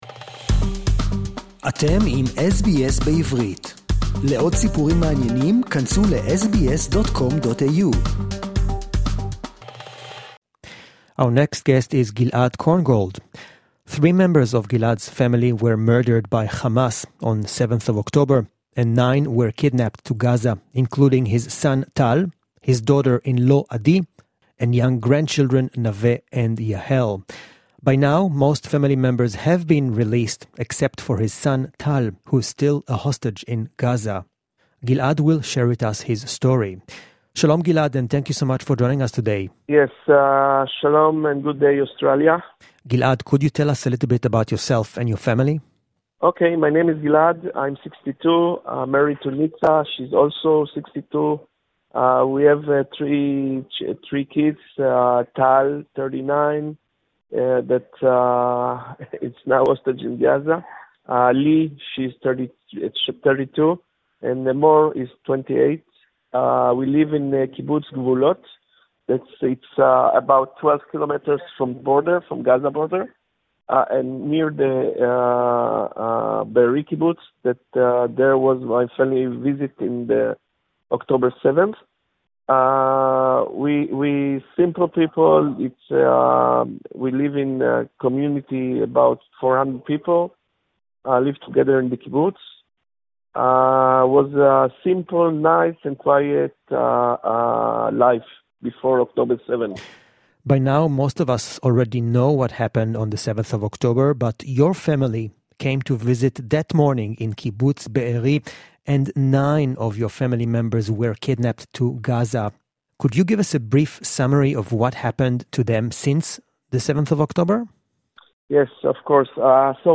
(English interview).